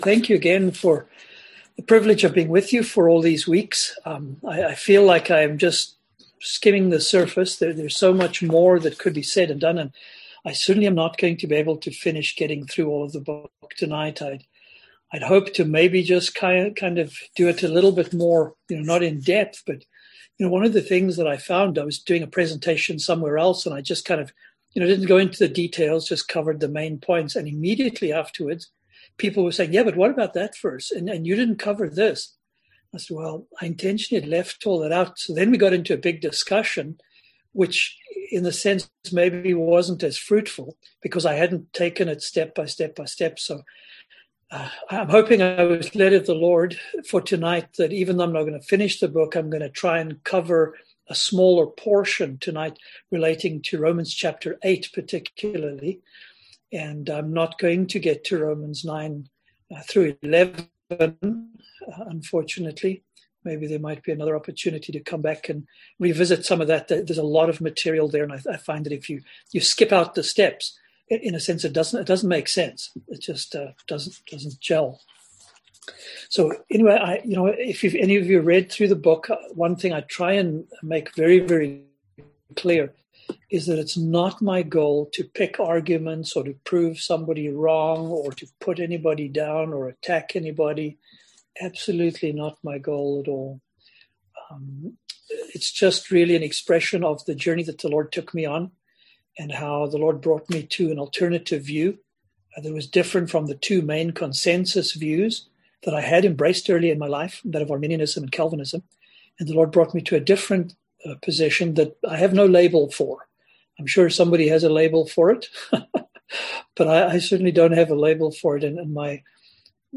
Passage: Romans 8:28-30 Service Type: Seminar